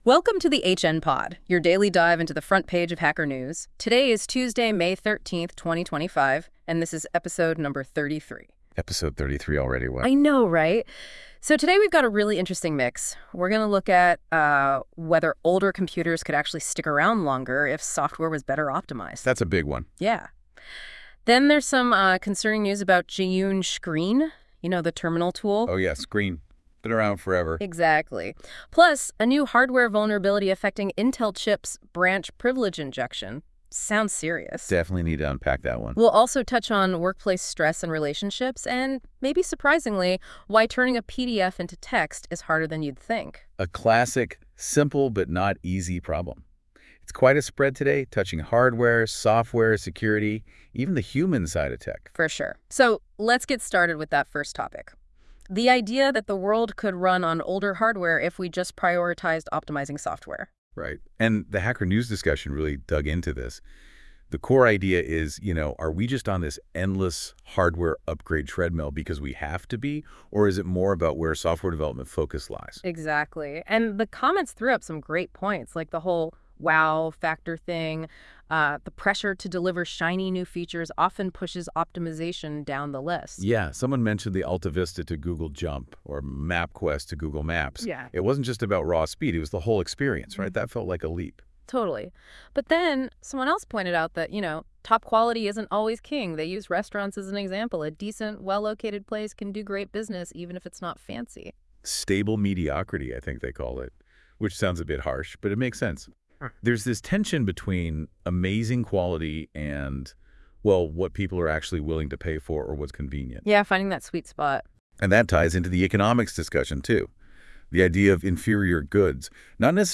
This episode is generated by 🤖 AI.